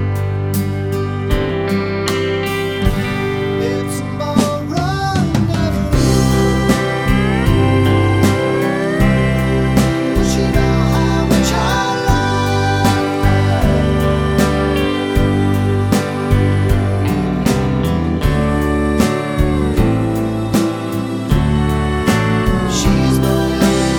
Country (Male)